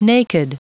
Prononciation du mot naked en anglais (fichier audio)
Prononciation du mot : naked
naked.wav